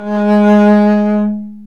Index of /90_sSampleCDs/Roland L-CD702/VOL-1/STR_Cb Bowed/STR_Cb1 mf vb